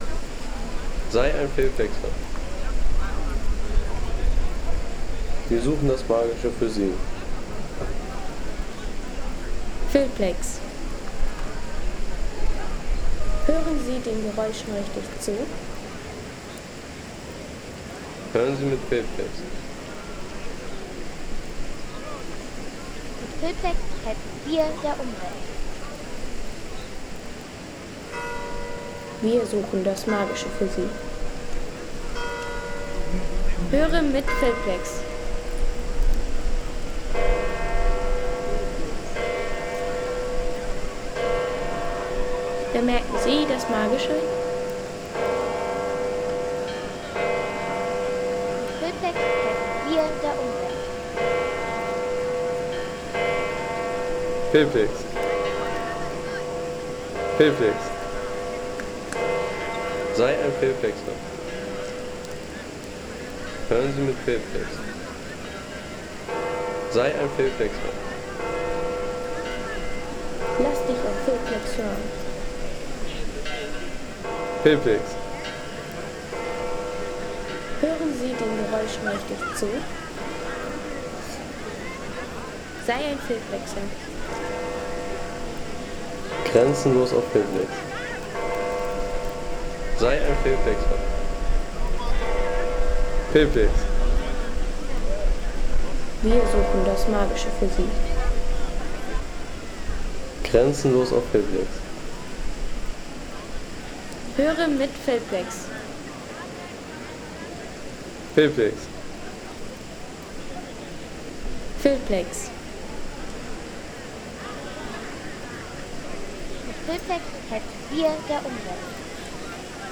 Petersplatz in Rom Sound | Feelplex
Atmosphärischer Stadtplatz-Sound aus Rom mit Besuchern, offenem Platzgefühl und urbaner Reisestimmung.
Lebendige Atmosphäre vom Petersplatz in Rom mit Besuchern, offenem Stadtraum und dem Flair eines weltberühmten Treffpunkts.